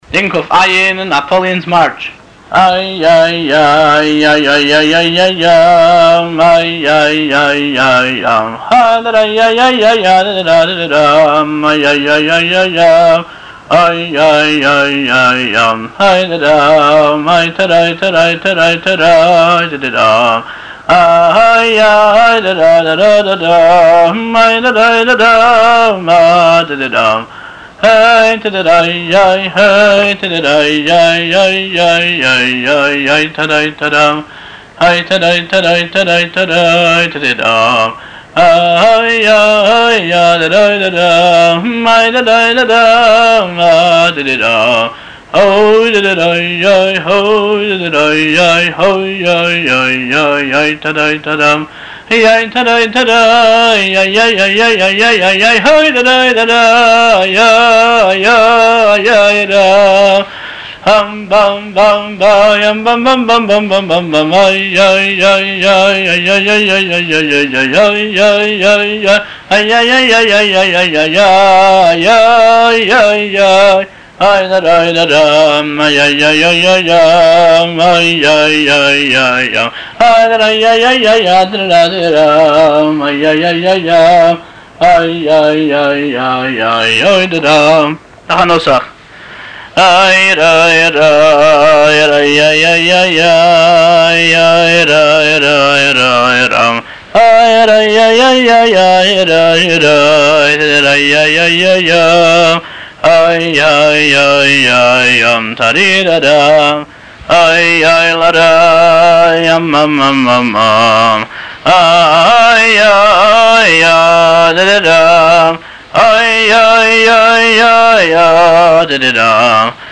הניגון